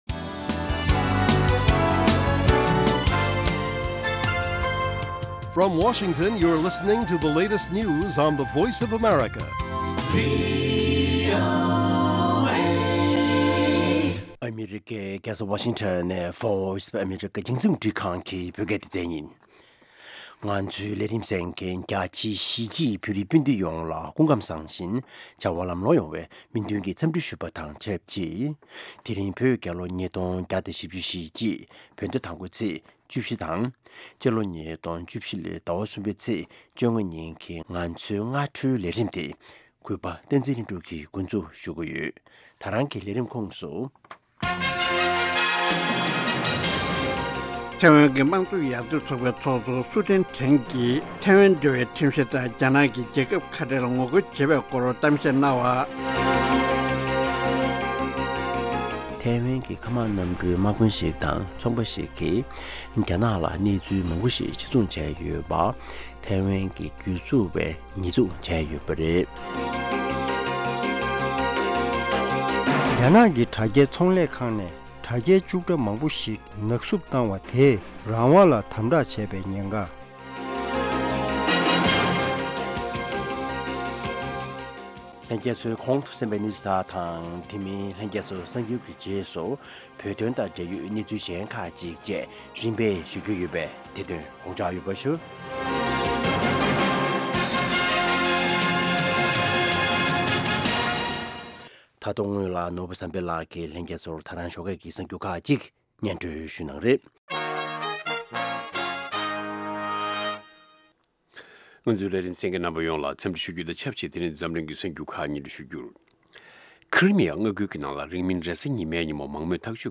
སྔ་དྲོའི་གསར་འགྱུར། ཉིན་ལྟར་ཐོན་བཞིན་པའི་བོད་དང་ཨ་རིའི་གསར་འགྱུར་ཁག་དང་། འཛམ་གླིང་གསར་འགྱུར་ཁག་རྒྱང་སྲིང་ཞུས་པ་ཕུད། དེ་མིན་དམིགས་བསལ་ལེ་ཚན་ཁག་ཅིག་རྒྱང་སྲིང་ཞུ་བཞིན་ཡོད། རྒྱང་སྲིང་དུས་ཚོད། Daily བོད་ཀྱི་དུས་ཚོད། 08:00 འཛམ་གླིང་གཅིག་གྱུར་གྱི་དུས་ཚོད། 0000 ལེ་ཚན་རིང་ཐུང་། 60 གསན་ན། MP༣ Podcast